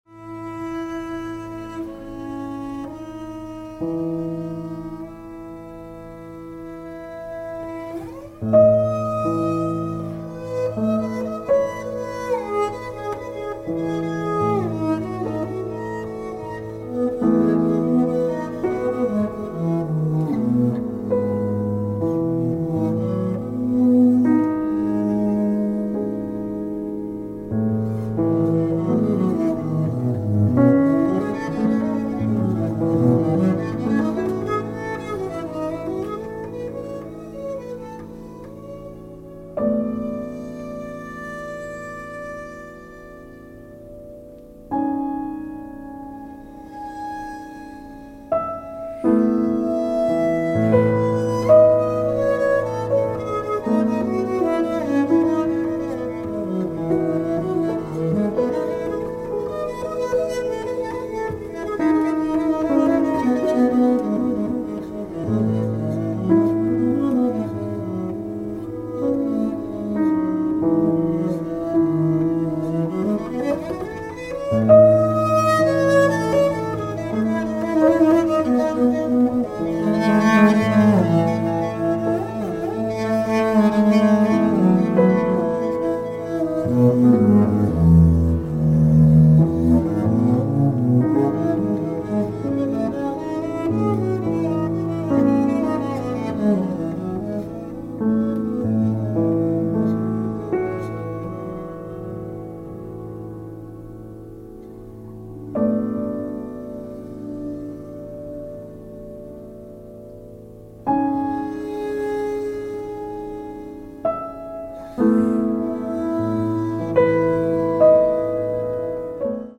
cellist